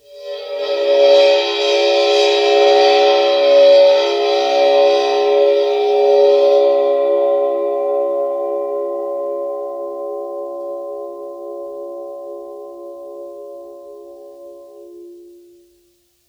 susCymb1-bow-1.wav